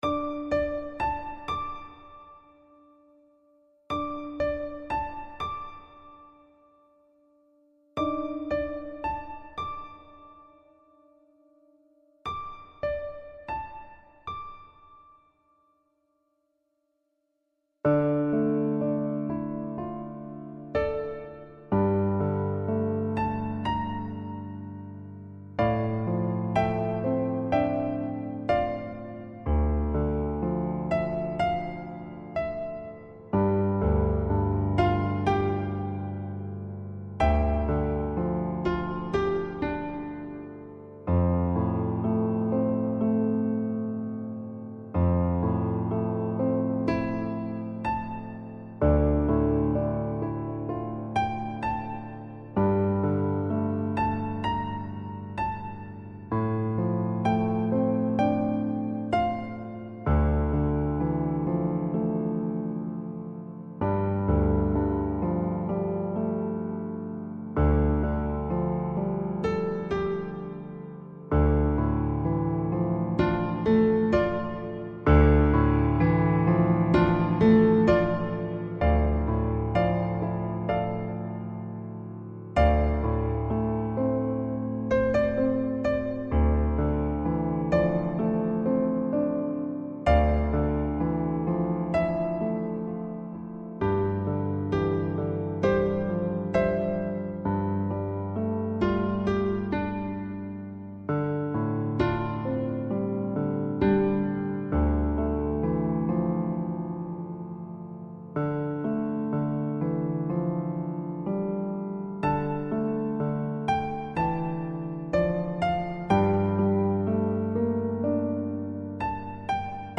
F 调纯钢琴伴奏